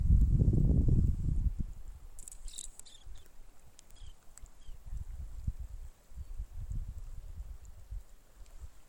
Baird´s Sandpiper (Calidris bairdii)
Detailed location: Ruta 40- San José
Condition: Wild
Certainty: Photographed, Recorded vocal